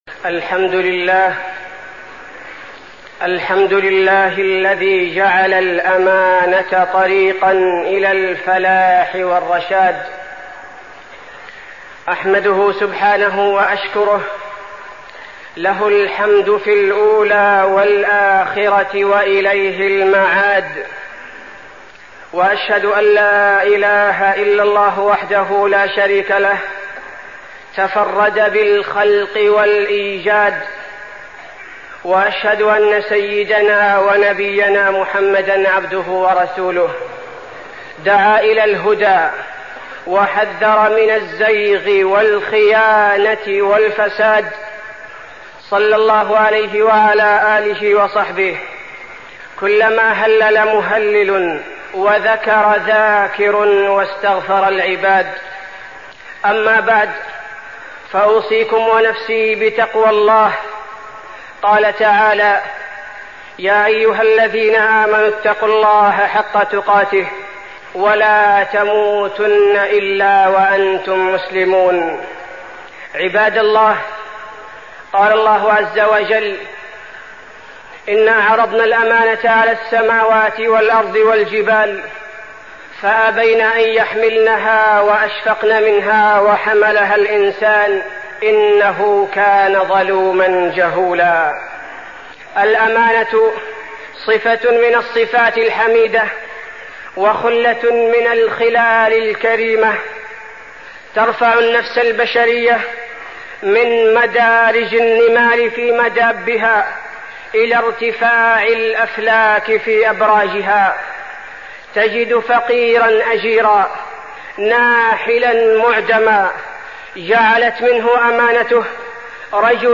تاريخ النشر ٢ رجب ١٤١٦ هـ المكان: المسجد النبوي الشيخ: فضيلة الشيخ عبدالباري الثبيتي فضيلة الشيخ عبدالباري الثبيتي الأمانة